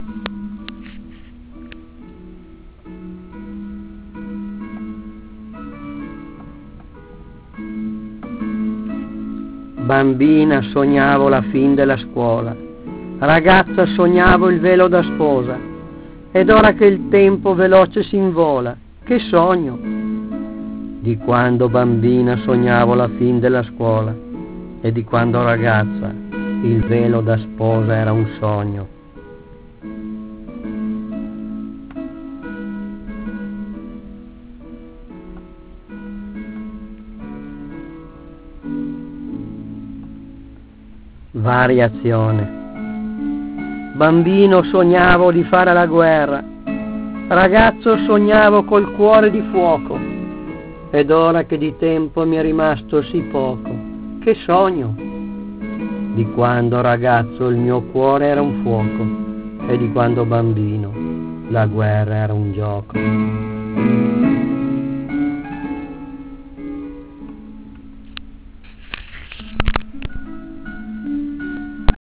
La poesia sonora si collega per un verso alla musica e per un altro al teatro, da un lato abbina il testo poetico ad un brano musicale, dall'altro sfrutta la sonorità del linguaggio.